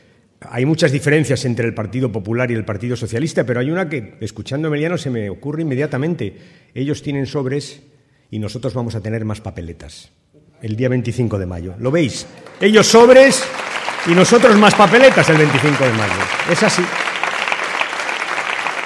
En el Teatro Victoria, de Talavera de la Reina, donde 600 militantes y simpatizantes socialistas han acompañado a Rubalcaba, y donde han intervenido además el líder de los socialistas castellano-manchegos, Emiliano García Page, y el candidato al Parlamento Europeo Sergio Gutiérrez, el Secretario General del PSOE ha recordado además que Cospedal se estrenó como presidenta de Castilla-La Mancha quitando las ayudas a las mujeres víctimas de la violencia de género, “precisamente las mujeres que más ayuda necesitan”.